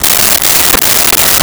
Saw Wood 02
Saw Wood 02.wav